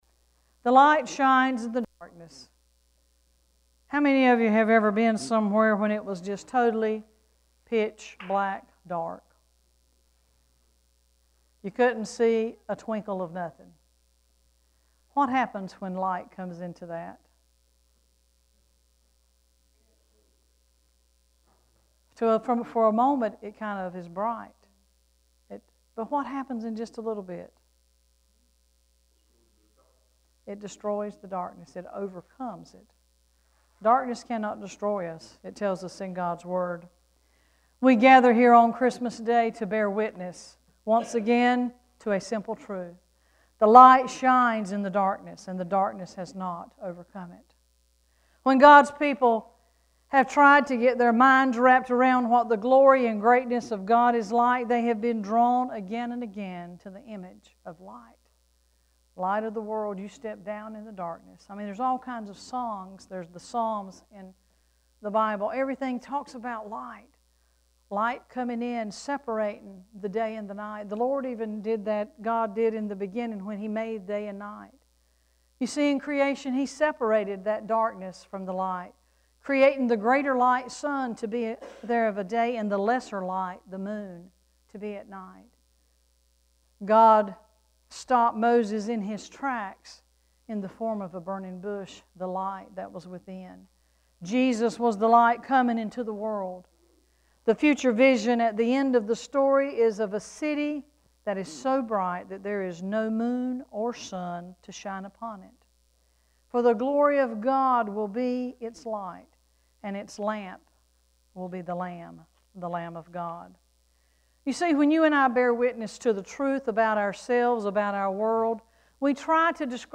12-25-sermon.mp3